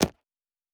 pgs/Assets/Audio/Sci-Fi Sounds/Interface/Click 8.wav at master
Click 8.wav